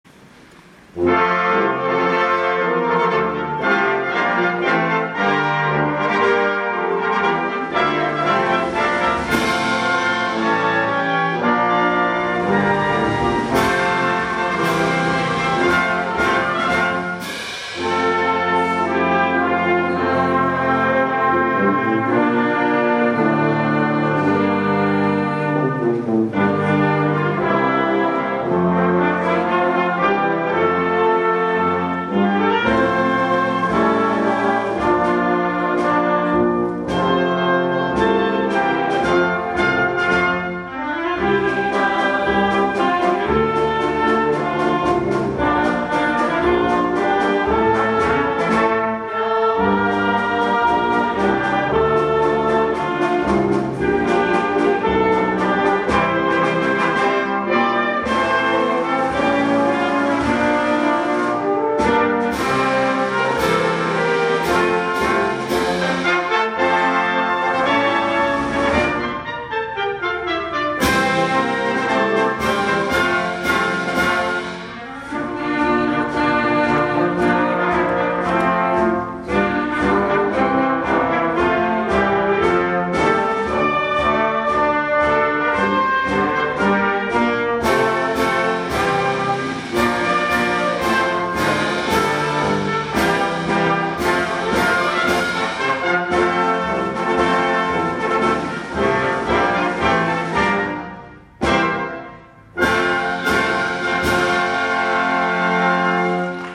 作詞：押切　順　三
作曲：佐藤　長太郎
令和元年６月２６日に行った陸上自衛隊第９音楽隊　雄勝高校閉校記念＆雄勝キャンパス開校記念演奏会で演奏された校歌　→